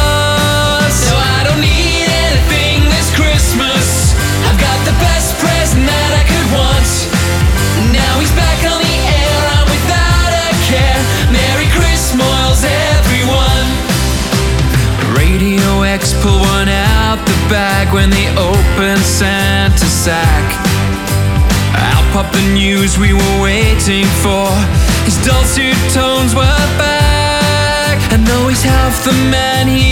• Pop/Rock